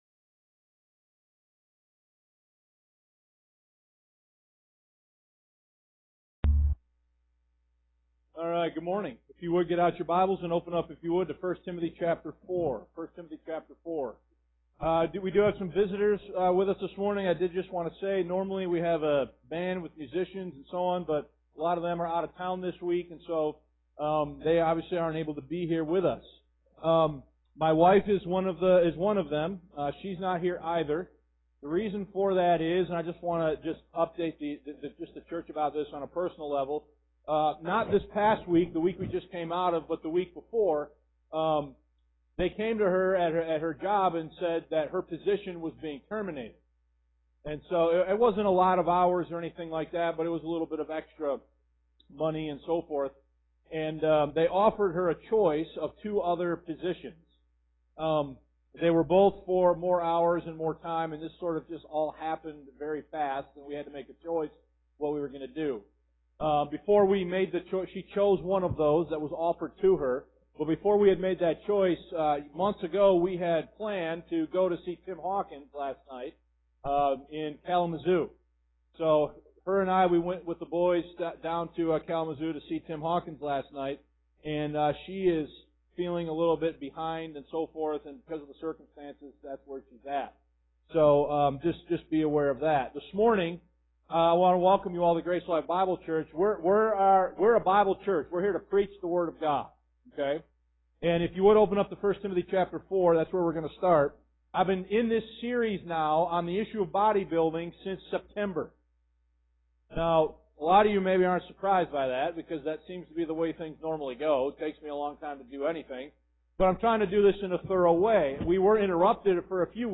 Sermon-Mar21.m4a